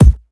Thick Kick Sample C Key 747.wav
Royality free bass drum single hit tuned to the C note. Loudest frequency: 408Hz
.WAV .MP3 .OGG 0:00 / 0:01 Type Wav Duration 0:01 Size 26,52 KB Samplerate 44100 Hz Bitdepth 16 Channels Mono Royality free bass drum single hit tuned to the C note.
thick-kick-sample-c-key-747-az9.ogg